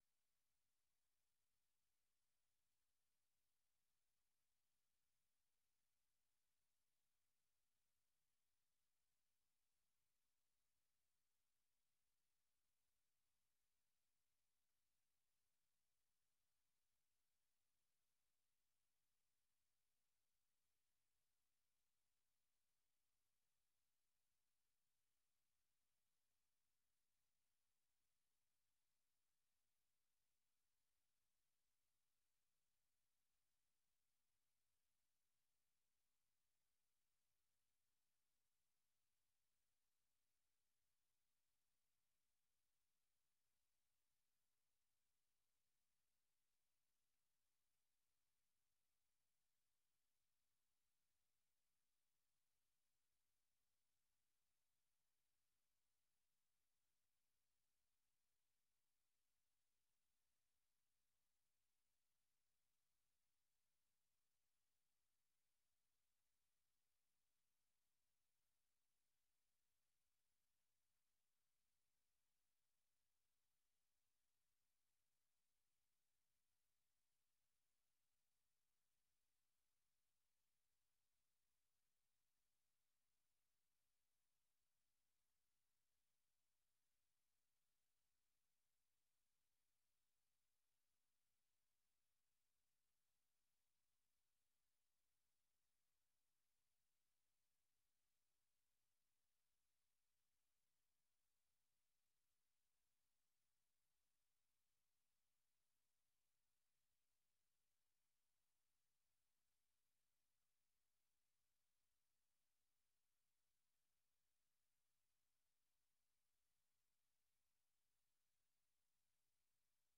El punto de encuentro para analizar y debatir, junto a expertos, los temas de la semana.